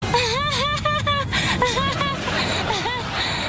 Ariana Grande Laughing Sound Button: Unblocked Meme Soundboard